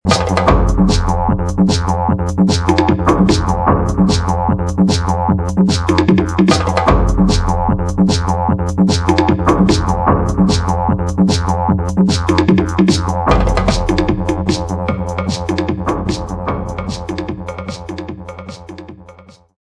Didj & Percussion & Electronics